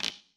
surface_wood_tray4.mp3